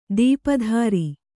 ♪ dīpa dhāri